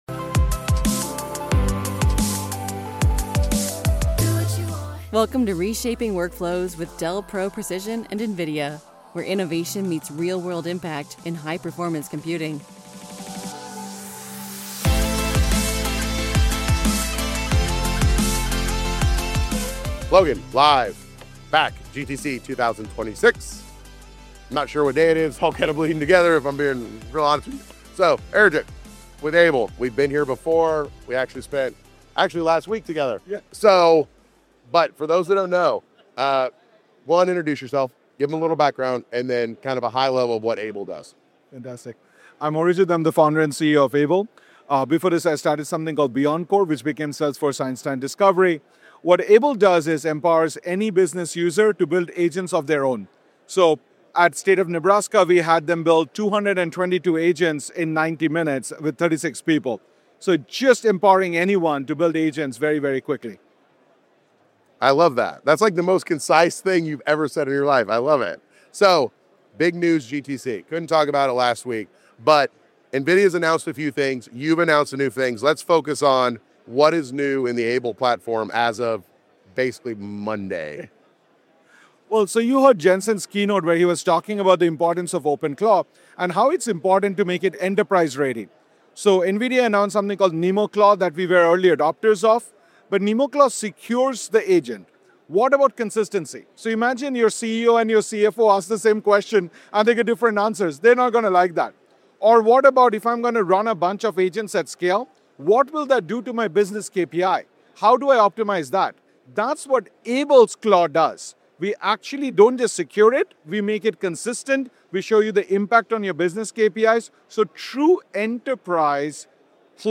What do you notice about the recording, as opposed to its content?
Live from GTC